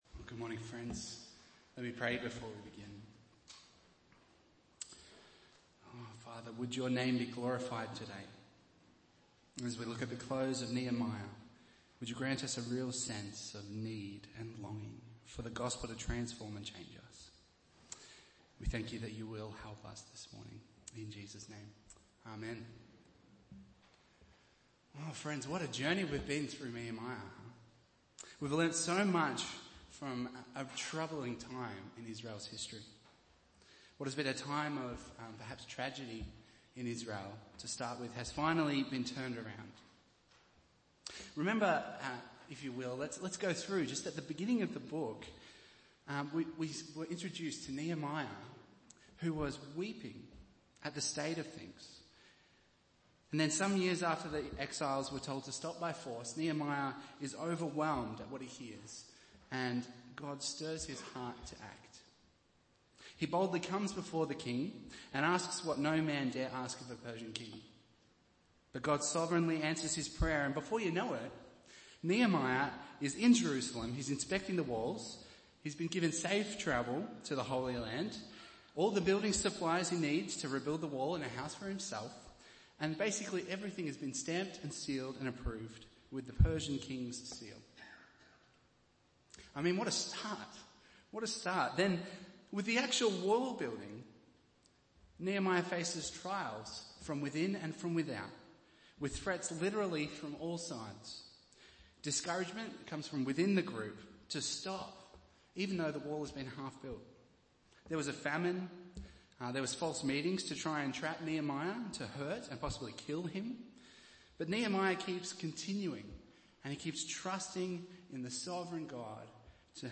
Bible Text: Nehemiah 13:1-14 | Preacher